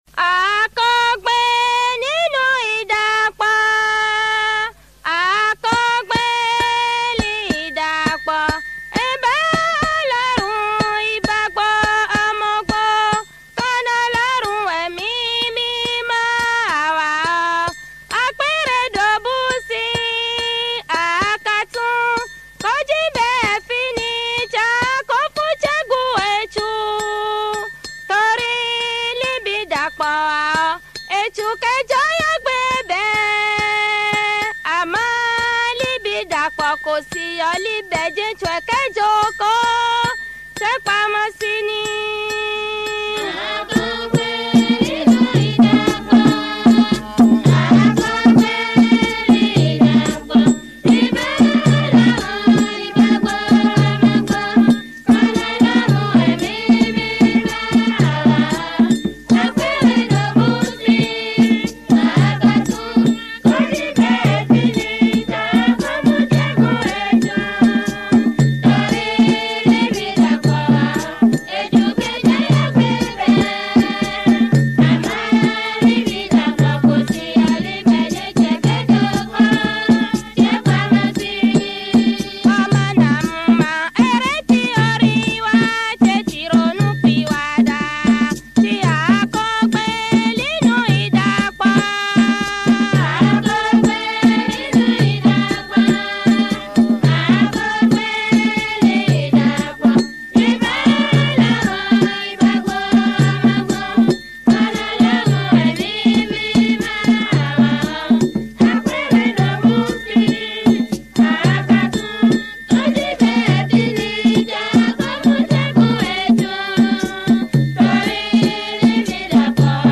Ici vous allez écouter une chanson religieuse.
chanson réligieuse en idaasha